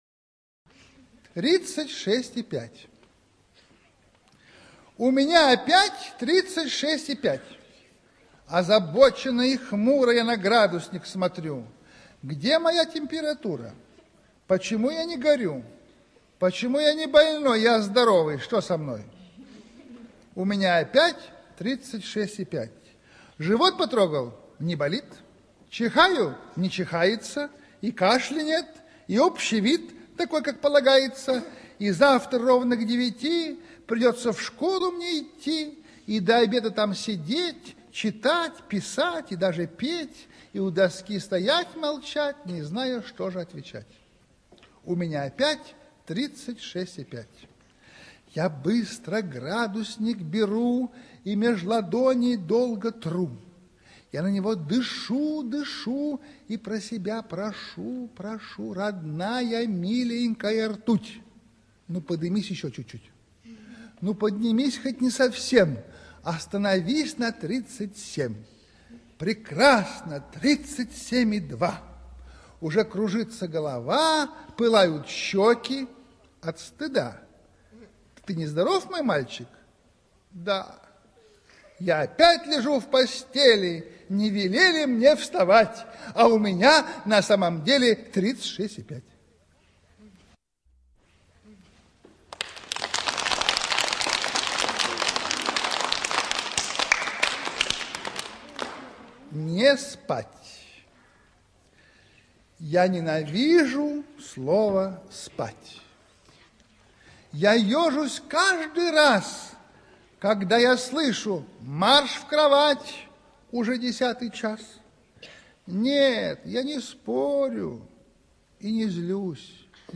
ЧитаетАвтор
ЖанрДетская литература, Поэзия